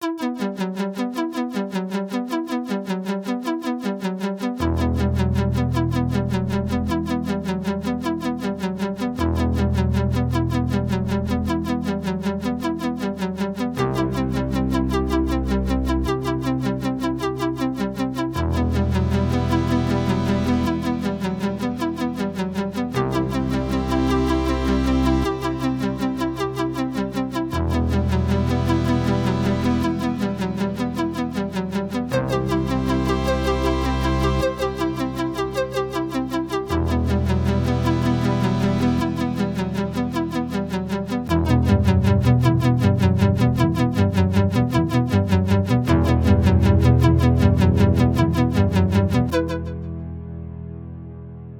Jeden Tag im Oktober ein Musikstück für die richtige Halloween-Stimmung.